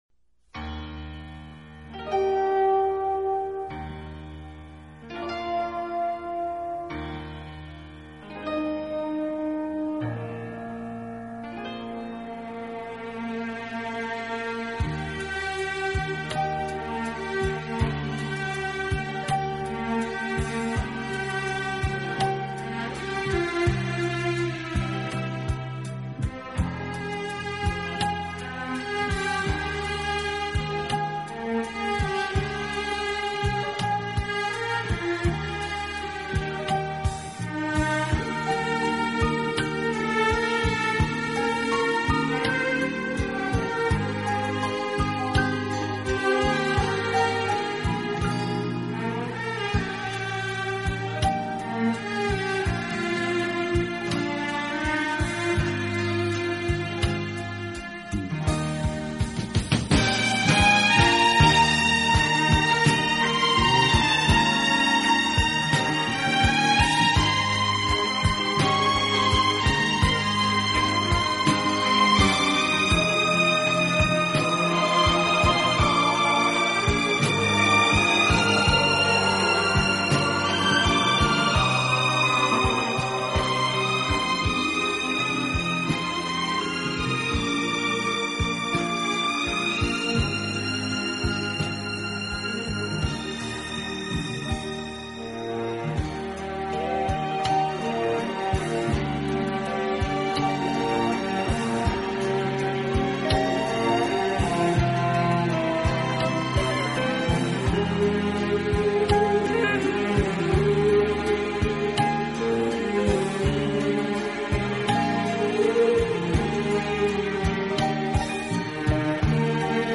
【轻音乐】
轻快、节奏鲜明突出，曲目以西方流行音乐为主。